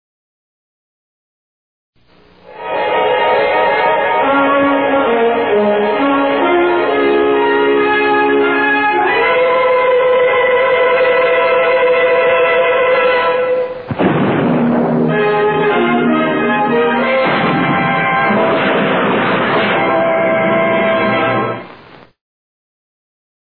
Opening Theme Click hereClosing Theme